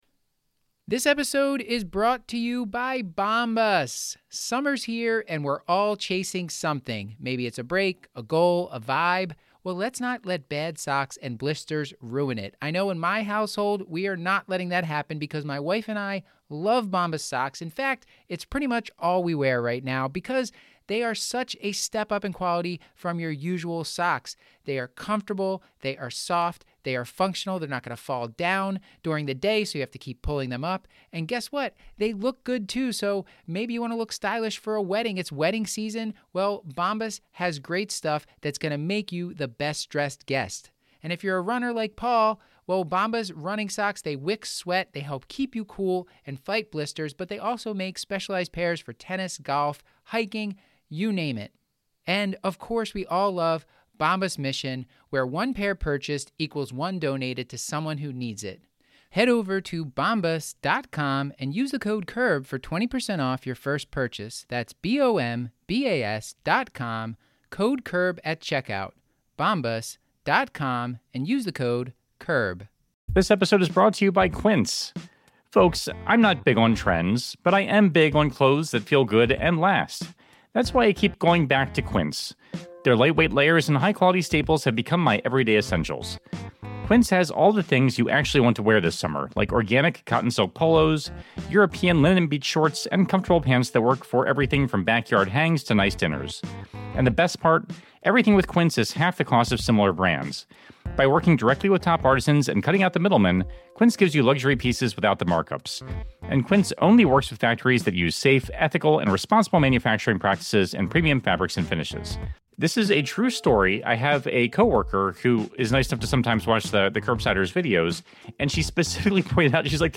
Listen as the pioneers of the COVID pandemic share their experiences taking care of some of the first COVID patients in the US. Our guests have a broad range of expertise and experiences.